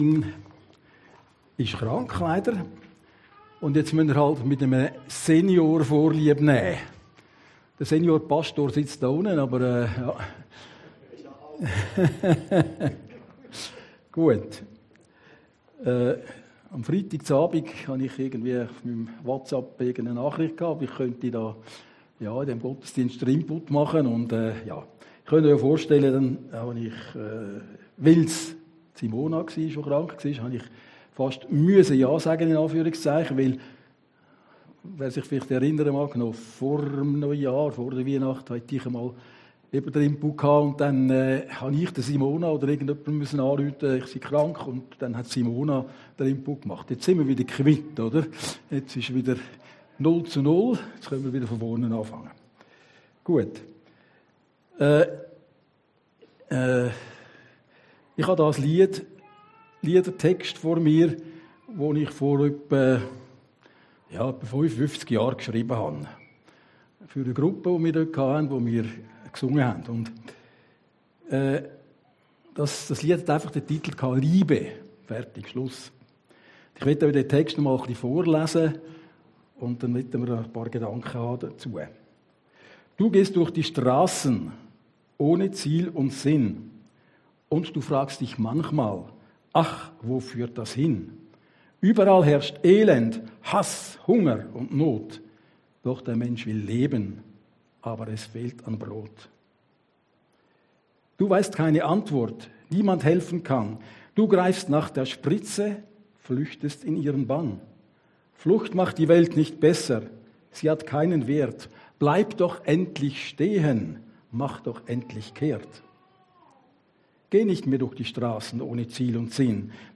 Predigten des Heilsarmee Korps Aargau Süd (Reinach AG)